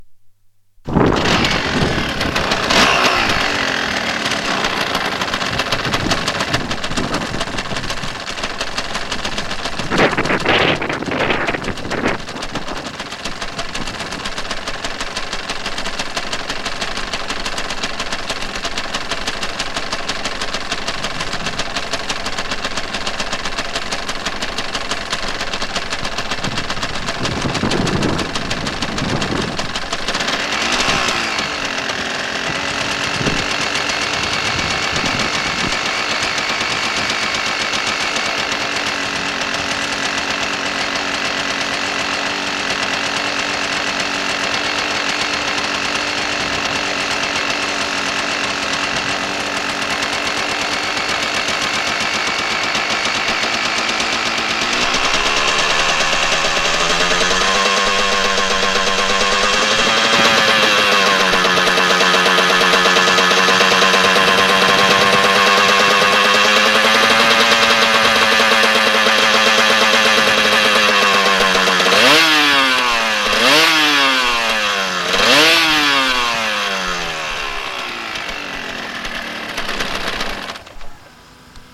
測定位置は下写真の通り、サイレンサーのほぼ真横、2mくらい離れた位置にレコーダーをセットし、収録しました。
アイドリング → 3000rpm → 5000〜6000rpm → 8000〜9000rpmまでのブリッピングを3回 → 終了
なお、収録当日はあいにく風が強く、雑音がひどくて聞き苦しい箇所がありますがご容赦ください。
1. ノーマルチャンバー＋R.S.V.サイレンサー／mp3形式／1分22秒